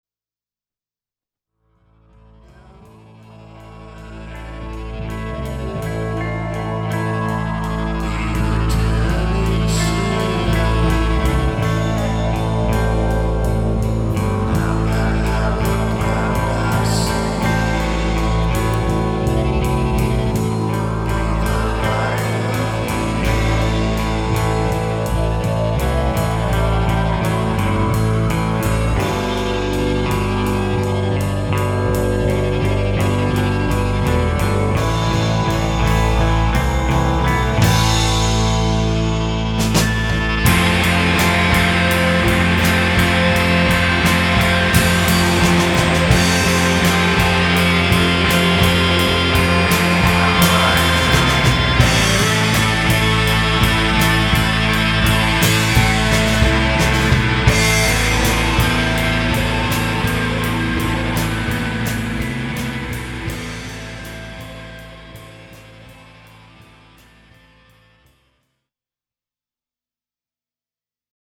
Recorded at Larry-O-Shack + The Drigh Room